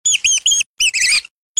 Mouse.mp3